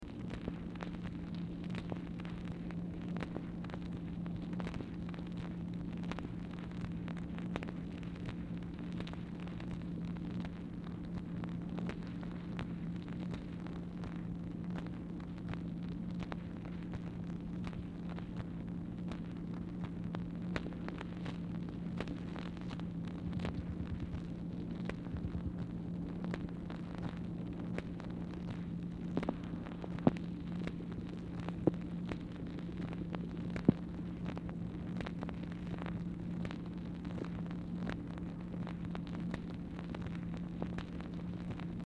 Telephone conversation # 2913, sound recording, MACHINE NOISE, 4/8/1964, time unknown | Discover LBJ
Format Dictation belt
Specific Item Type Telephone conversation